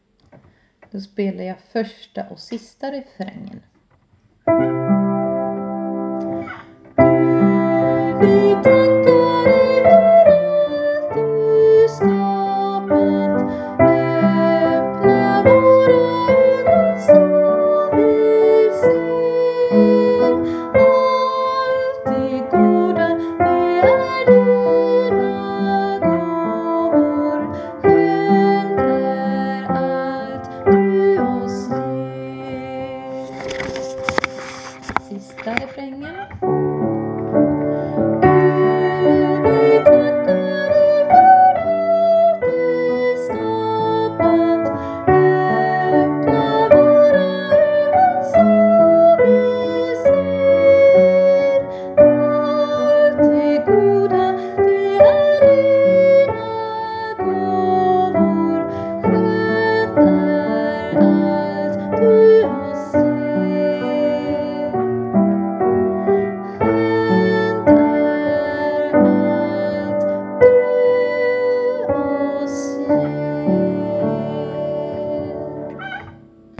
sopran